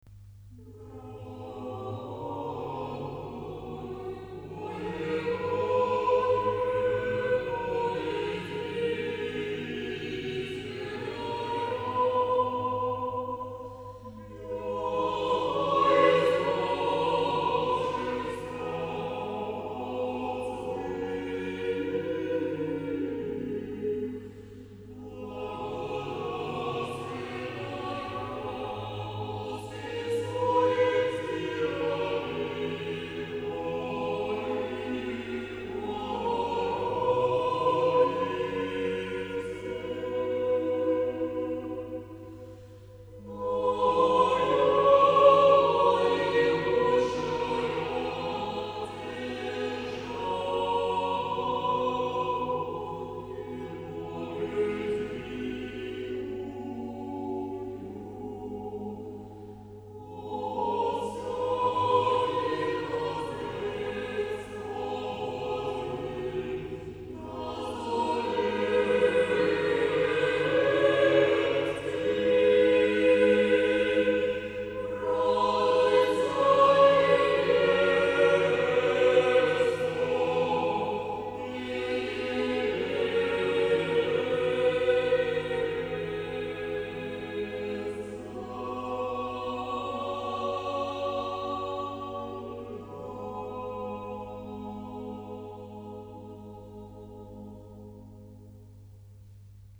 Православни песнопения